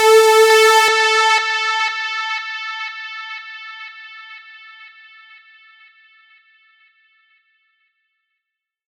Analog Str Stab.wav